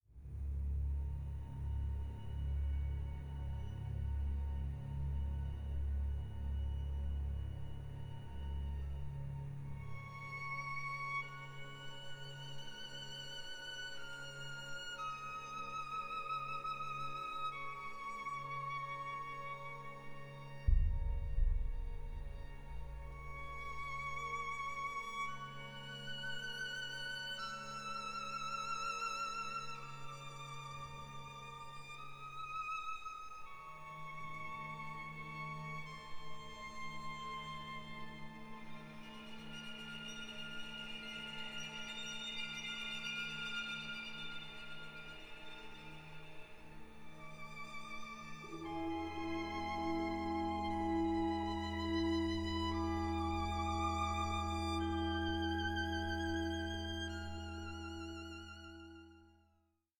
A COSMIC, ADVENTUROUS PAIRING OF VIOLIN CONCERTOS